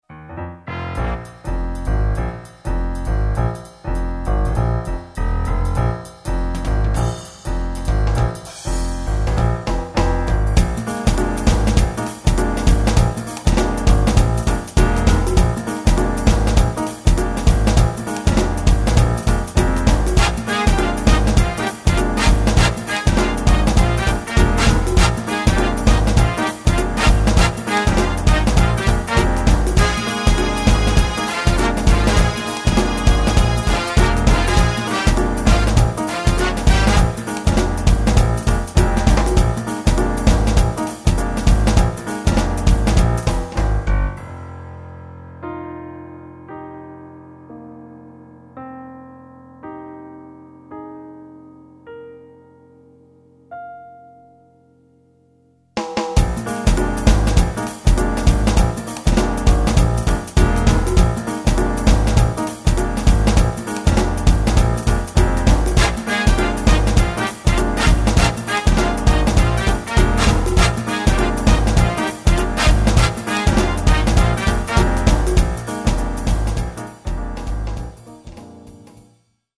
jazzig